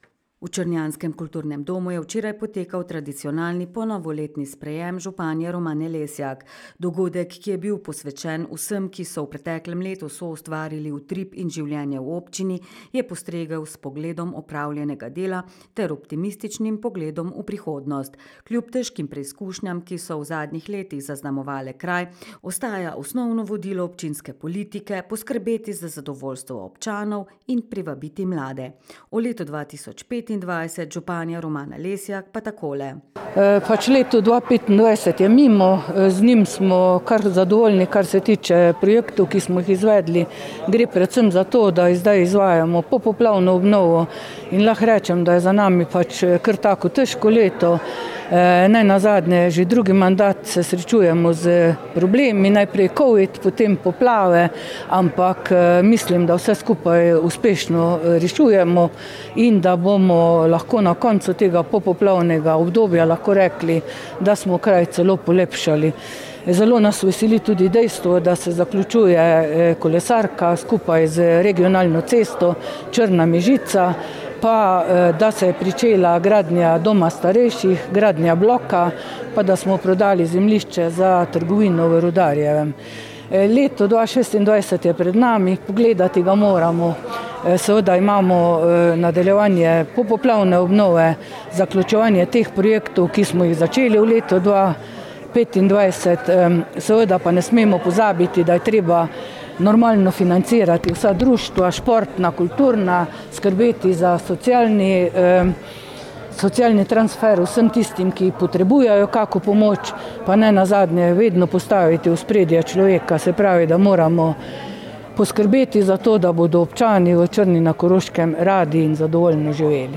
V črnjanskem kulturnem domu je včeraj potekal tradicionalni ponovoletni sprejem županje Romane Lesjak.
Kljub težkim preizkušnjam, ki so v zadnjih letih zaznamovale kraj, ostaja osnovno vodilo občinske politike jasno: poskrbeti za zadovoljstvo občanov in privabiti mlade. O letu 2025 županja Romana Lesjak pa takole: